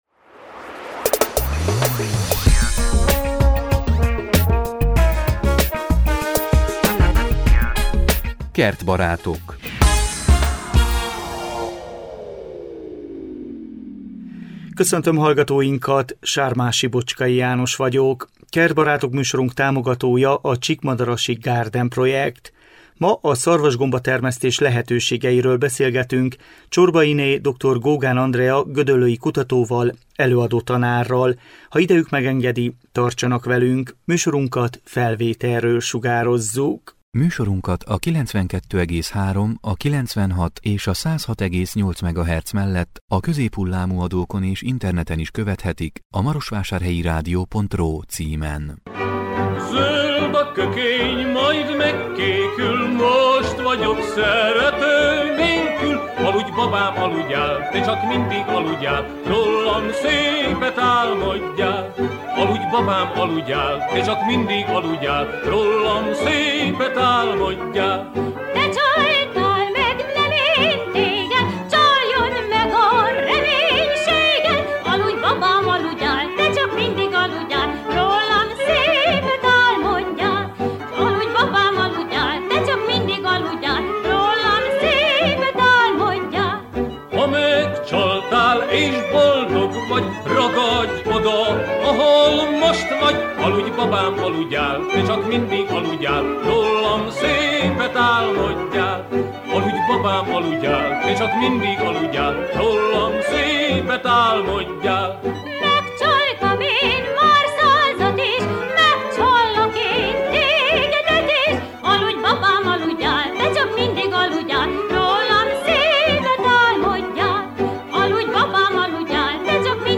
A szarvasgomba termesztés lehetőségeiről beszélgetünk